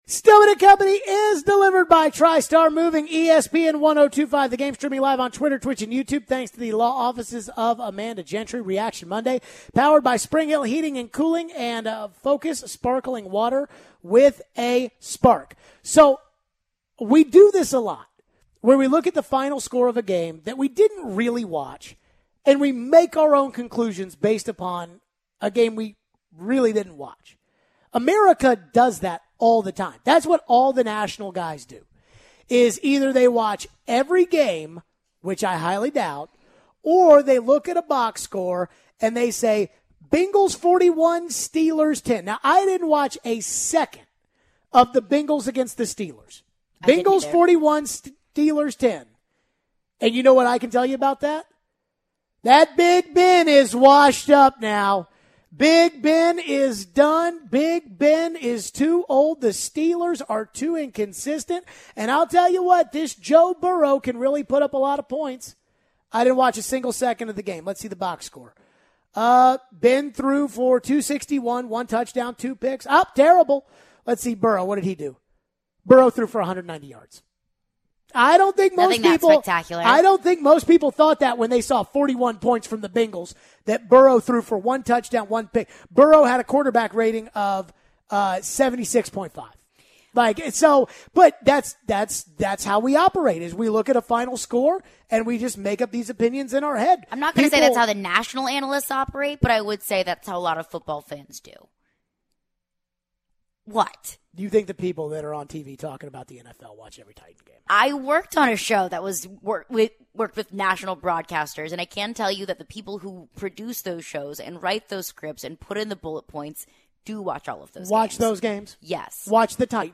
Vrabel coached a good game and thought the game was going as they wanted. We go back to the phones. Plus some thoughts on the college football coaching moves from this weekend.